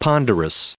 added pronounciation and merriam webster audio
603_ponderous.ogg